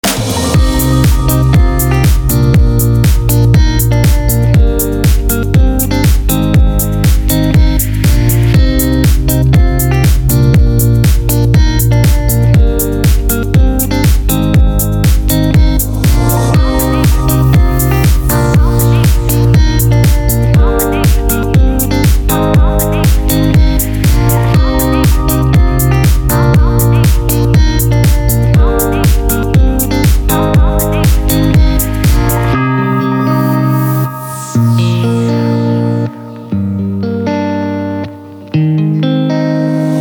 • Качество: 320, Stereo
deep house
спокойные
без слов